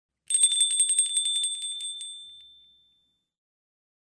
Звуки дверного колокольчика
Дверной колокольчик с изюминкой